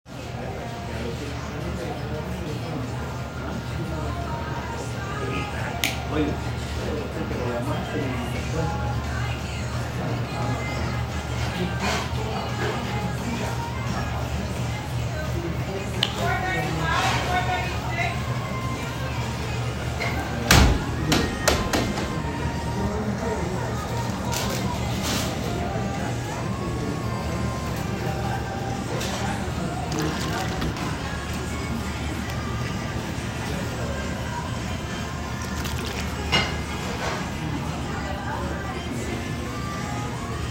Field Recording #1
Location: Dunkin’ on the Quad Sounds: Music, chattering, thuds and chairs moving, orders being called.
Field-Recording-1.m4a